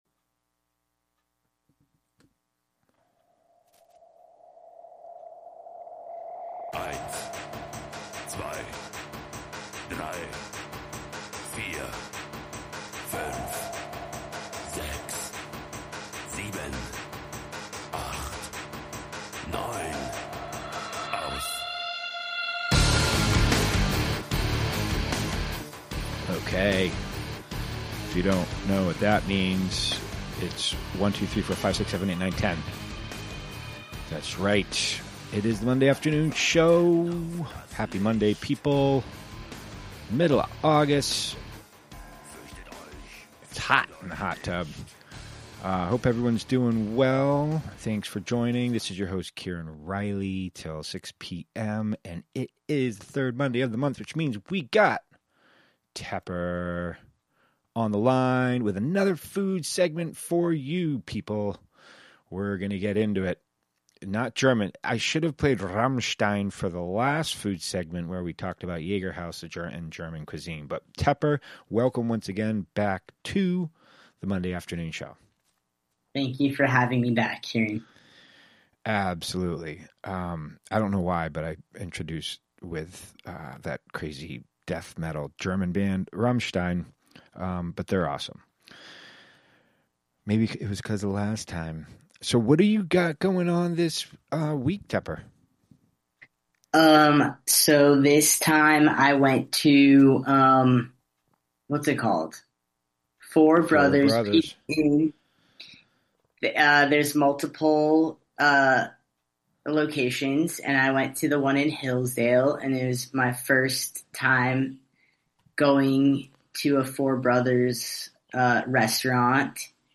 Tune in for a mix of music themed on current events, interviews with artists, musicians, community members, and more live from Housatonic, Mass.